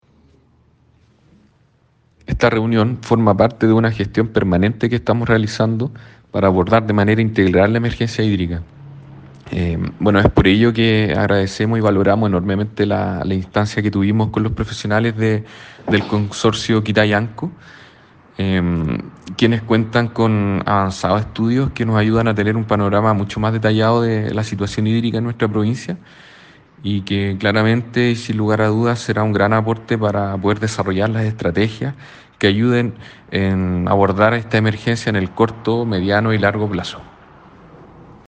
Según explica el Delegado presidencial provincial de Limarí, Galo Luna Penna